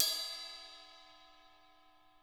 BELRIDE2.WAV